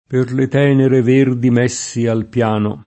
messe [m$SSe] s. f. («mietitura; biade») — es. con acc. scr.: il suol s’ammanta D’erbe e di mèssi?